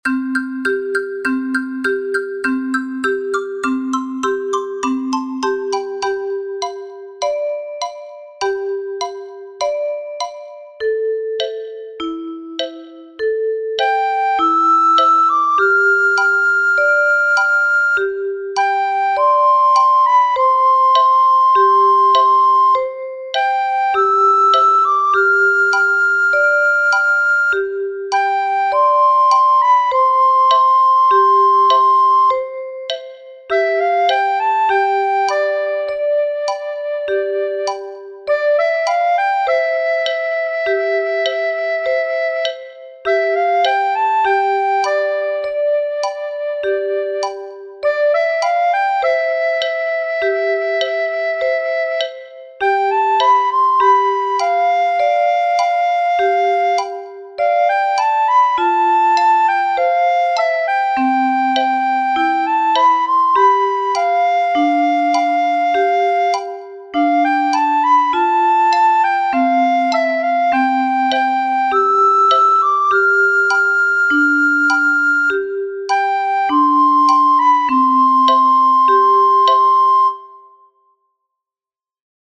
Flute Metalophone Bass Xylophone Repeat as many times as you need!!